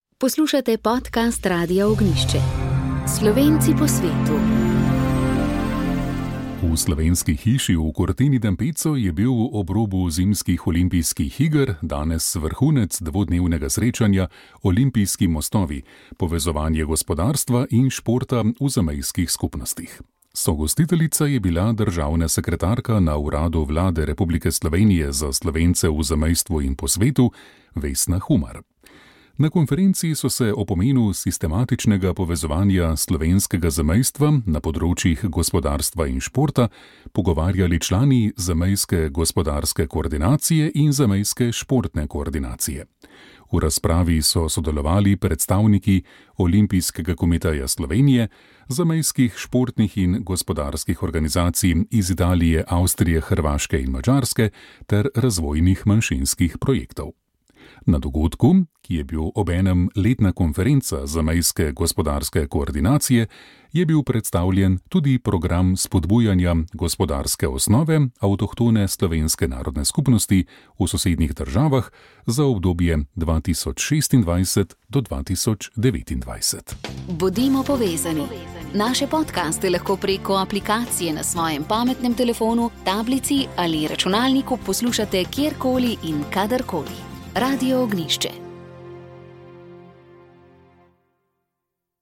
Rožni venec
Molili so radijski sodelavci.